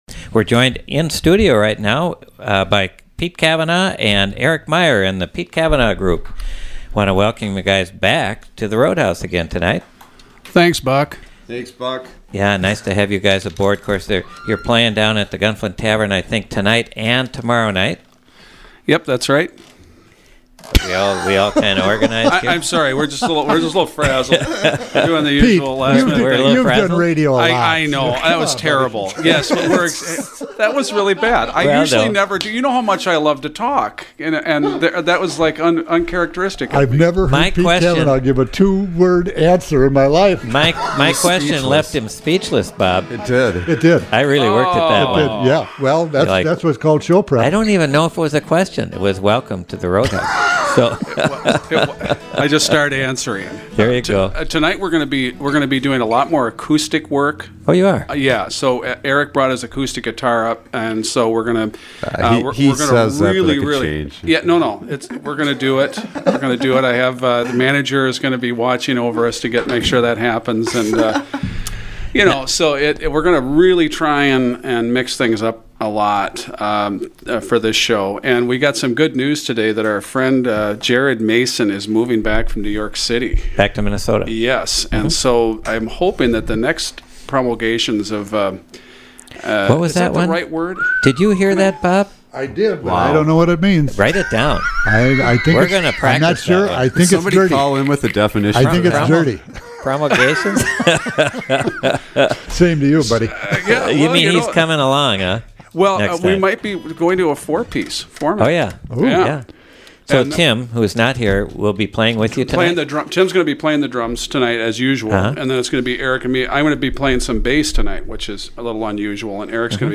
bring the blues to Studio A
music and talk